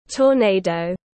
Lốc xoáy tiếng anh gọi là tornado, phiên âm tiếng anh đọc là /tɔːˈneɪ.dəʊ/.
Tornado /tɔːˈneɪ.dəʊ/